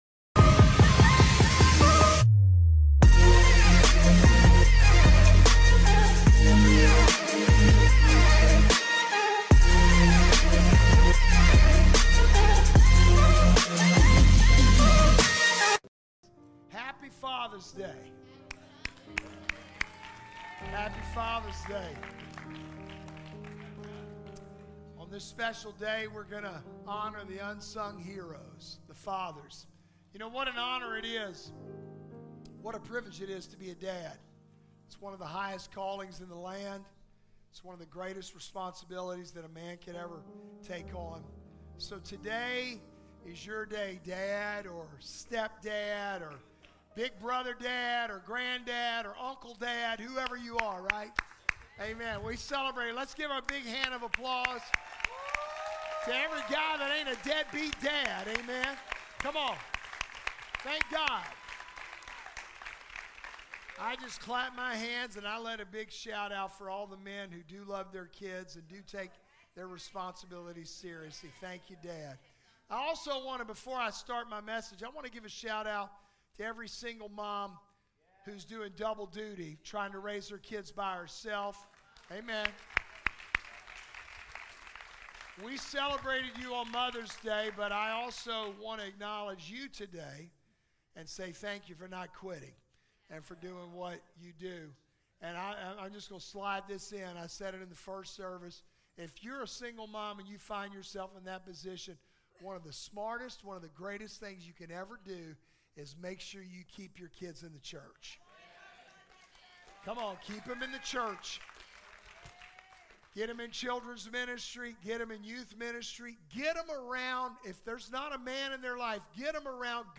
The sermon introduces the DREAM acronym: Dedicate yourself to God, Reserve time with Him, Evaluate...